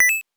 coin_3.wav